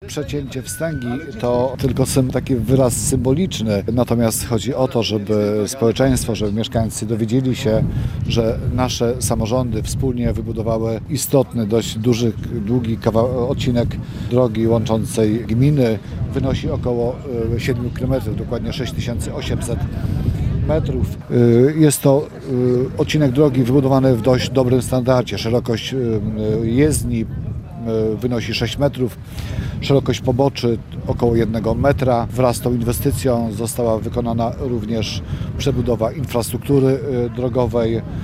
Ten odcinek został wybudowany w bardzo dobrym standardzie, szerokość jezdni wynosi 6 m, szerokość poboczy ok. 1 m – mówi starosta powiatu ostrołęckiego, Piotr Liżewski.